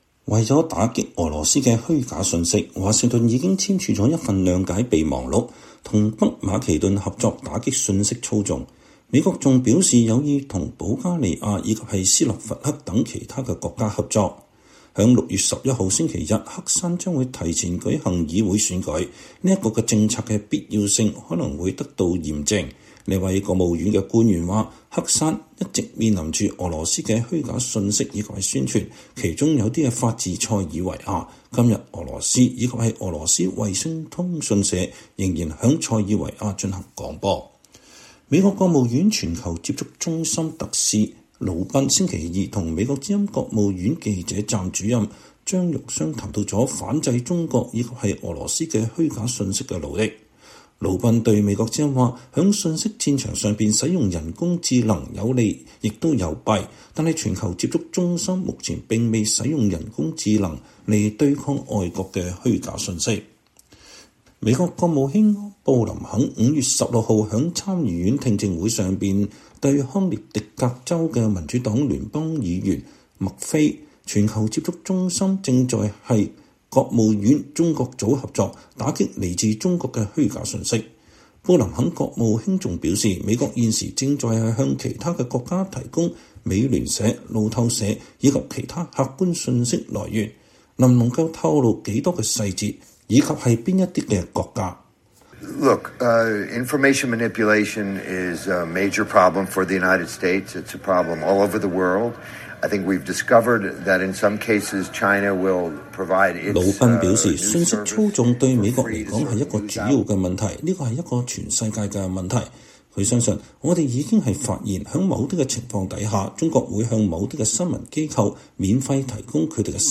VOA專訪全球接觸中心特使 談美國試圖與盟友簽署更多協議打擊中俄虛假信息